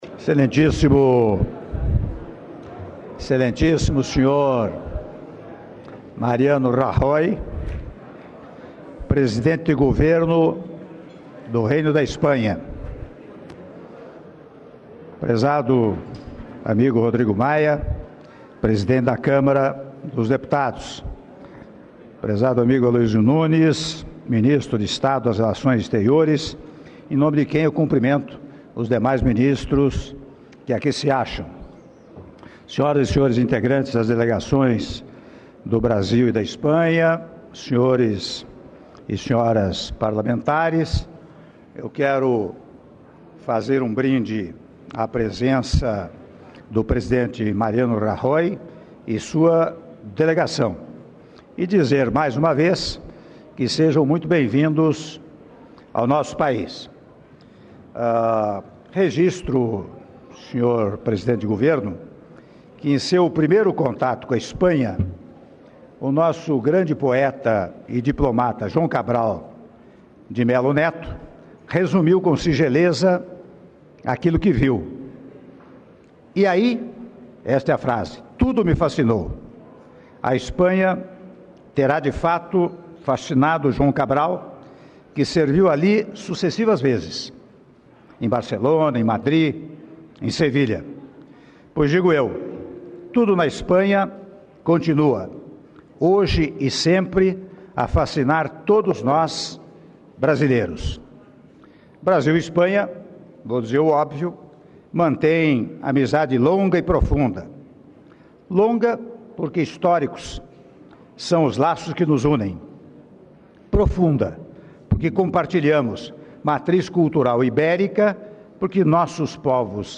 Áudio do Brinde do Presidente da República, Michel Temer, após Almoço oferecido ao Presidente do Governo da Espanha, Mariano Rajoy - (06min04s) - Brasília/DF — Biblioteca